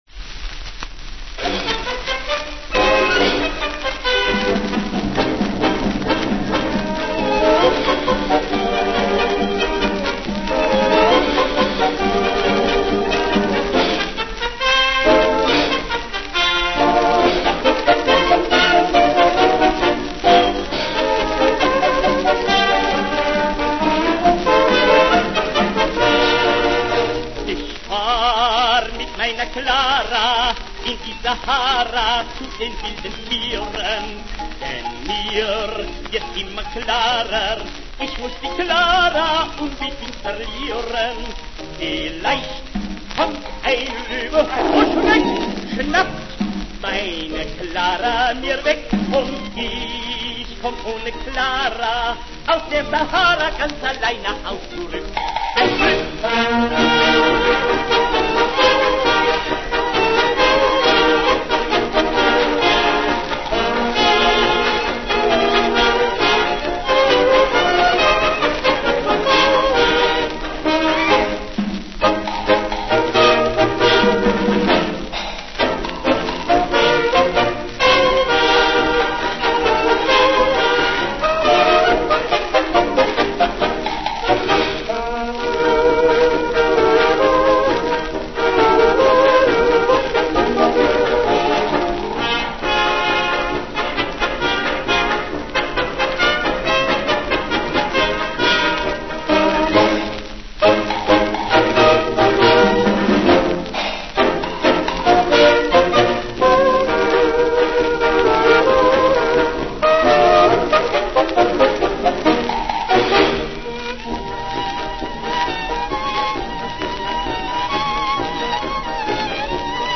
Foxtrott gespielt vom
mit Gesang